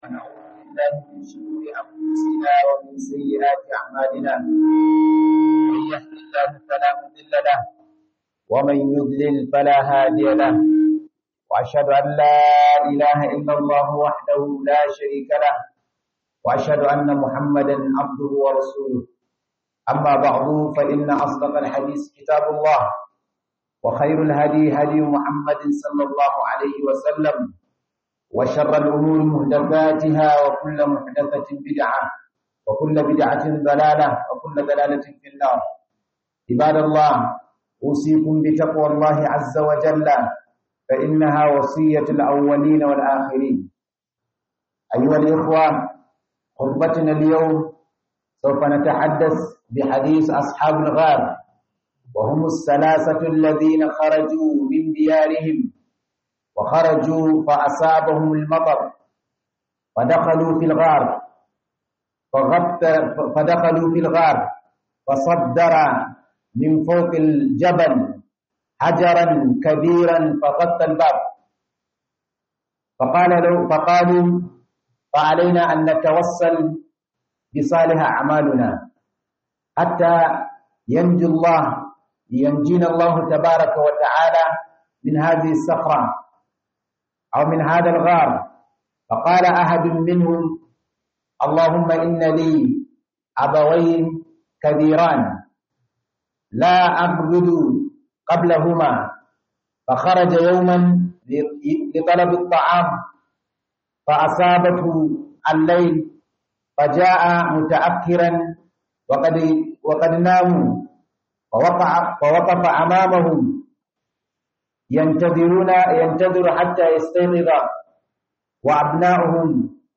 KHUDUBAR JUMA'A